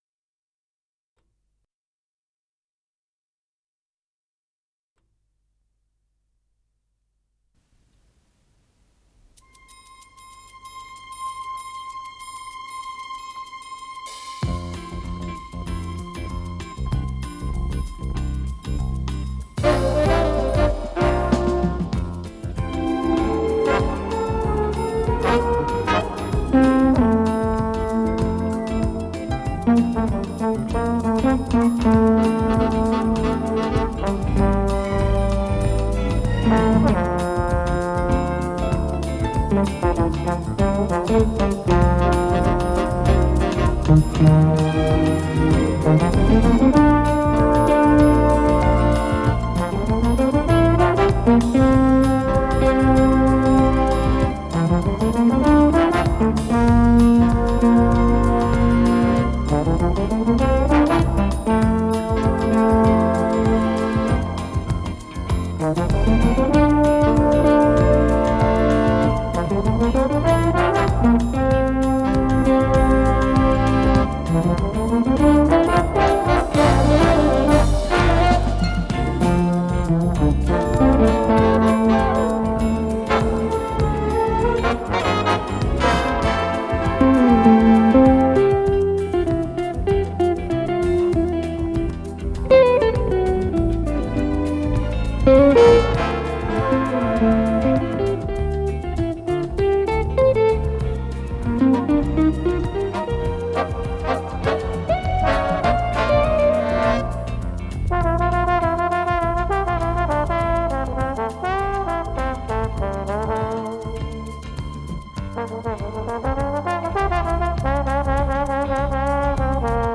с профессиональной магнитной ленты
ПодзаголовокПьеса для оркестра
гитара
тромбон
ВариантДубль моно